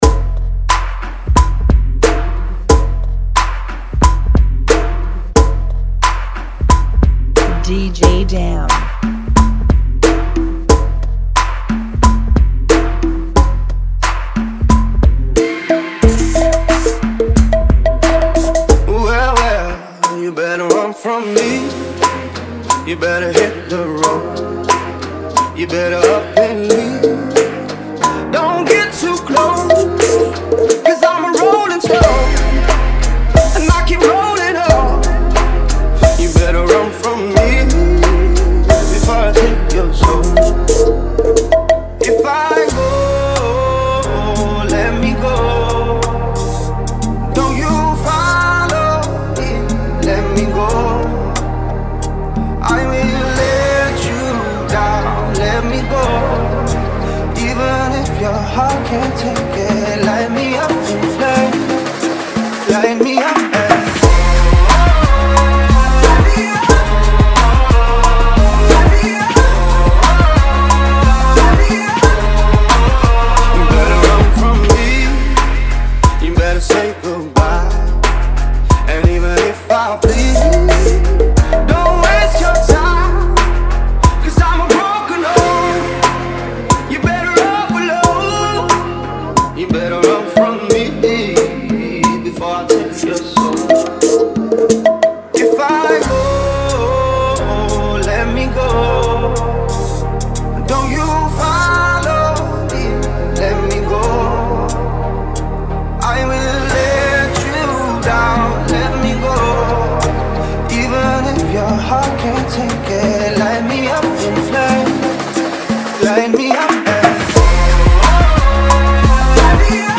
Genre: Bachata Remix